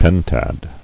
[pen·tad]